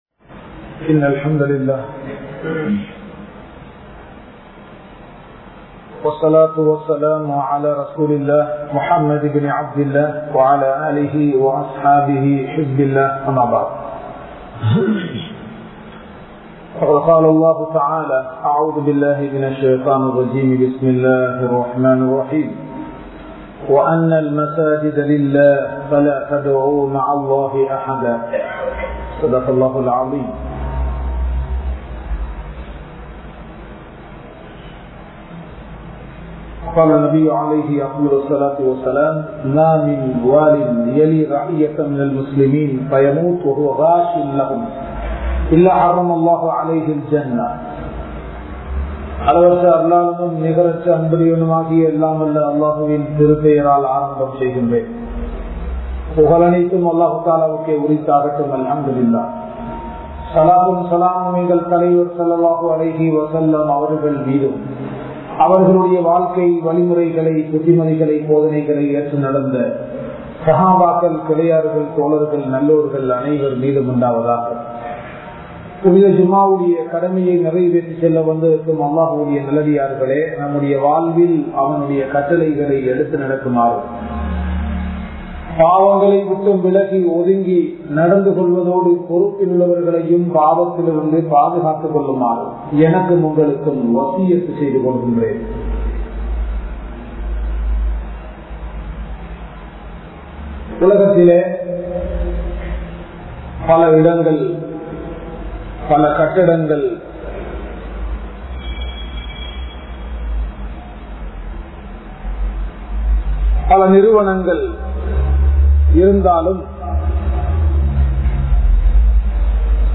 Masjidhin Niruvaahaththitku Yaar Thahuthi? (மஸ்ஜிதின் நிருவாகத்திற்கு யார் தகுதி?) | Audio Bayans | All Ceylon Muslim Youth Community | Addalaichenai
Saliheen Jumua Masjidh